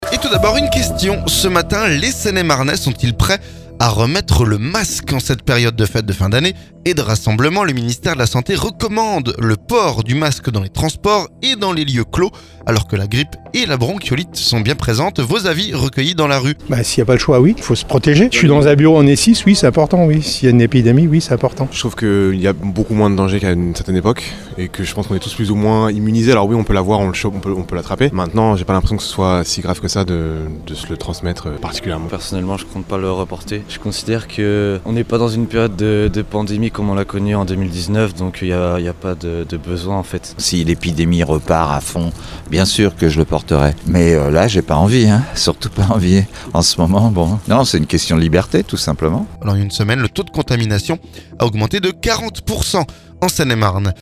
Vos avis recueillis dans la rue.